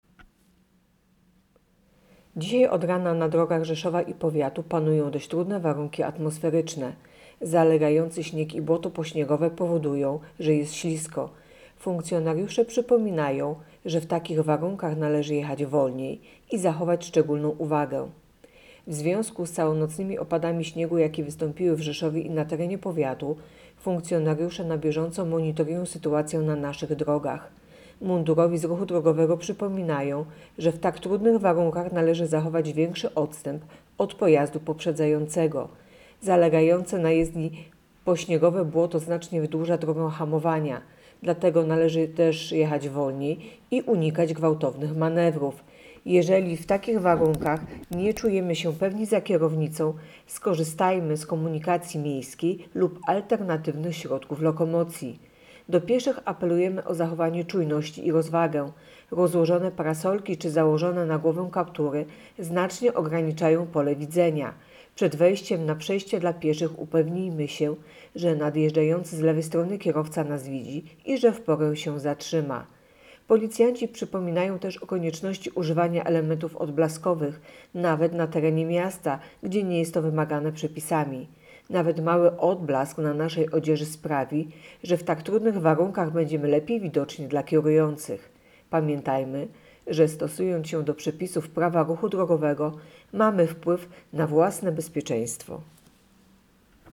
Opis nagrania: Nagranie informacji pt. Trudne warunki na drogach Rzeszowa i powiatu. Policjanci apelują o ostrożną jazdę.